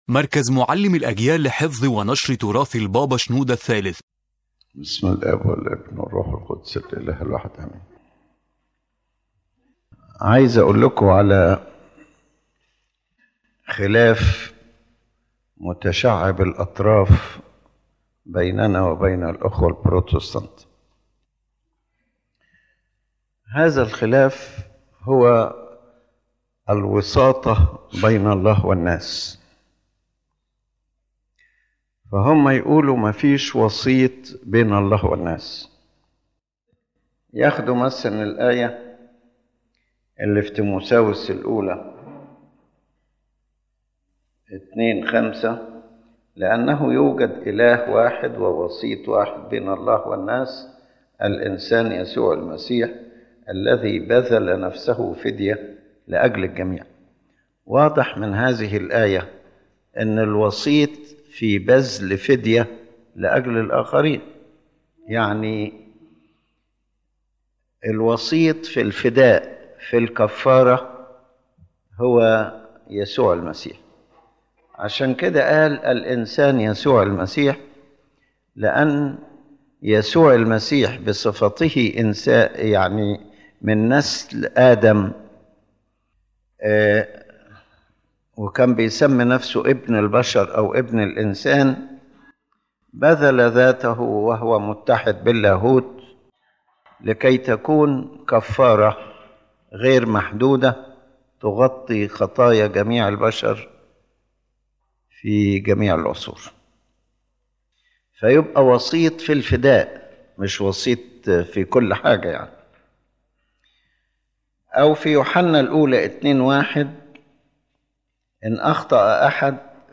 This lecture discusses the concept of mediation between God and humanity from a Coptic Orthodox perspective, clarifying the distinction between Christ’s unique redemptive mediation and the spiritual and ministerial mediation carried out by the Church according to Holy Scripture.